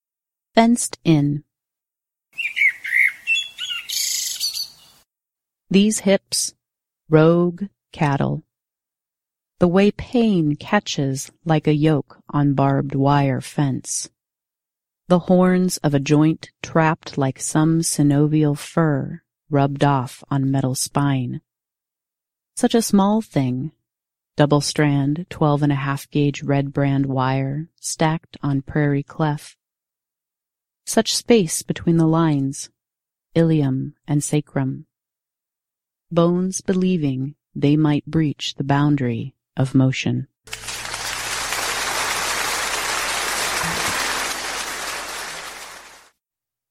“Fenced In” aired Thursday, April 30, 2015 as part of The Platte River Sampler.
The Platte River Sampler aired on Thursdays from 6PM – 7PM on 89.3 FM KZUM, Lincoln, Nebraska’s non-commercial, listener-sponsored community Radio Station.